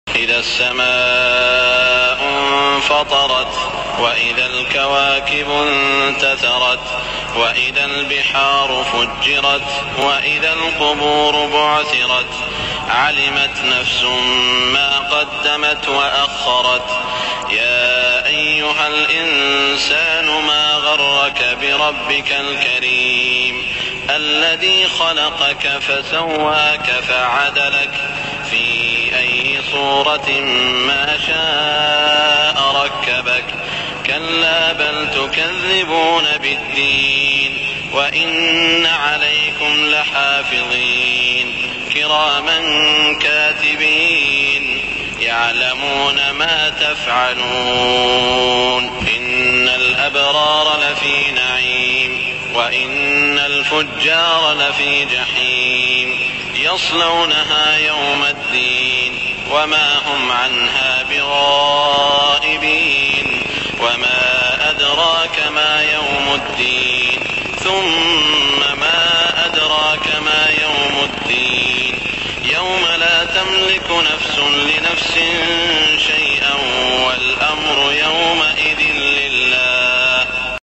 صلاة المغرب 1415هـ سورة الانفطار > 1415 🕋 > الفروض - تلاوات الحرمين